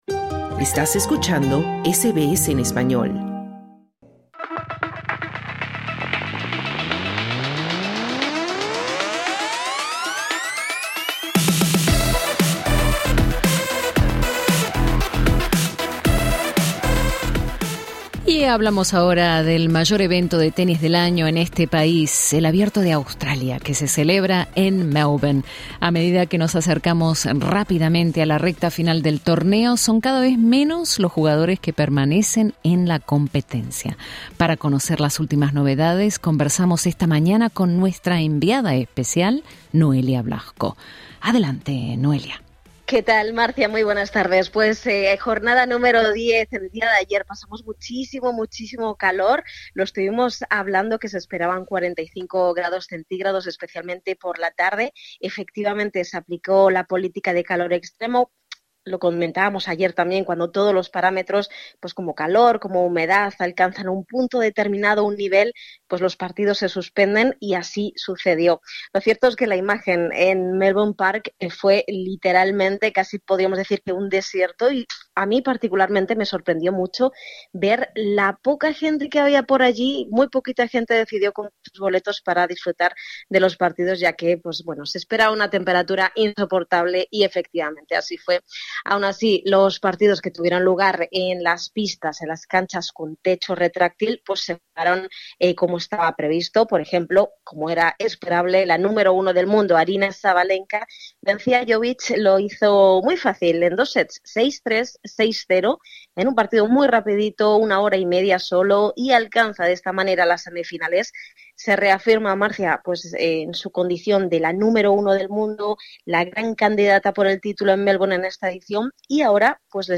Escucha el informe deportivo en el podcast situado en la parte superior de esta página.